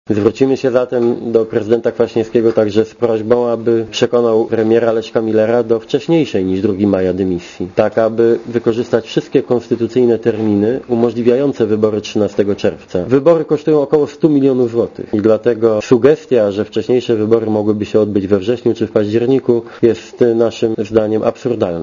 Mówi Donald Tusk (90 KB)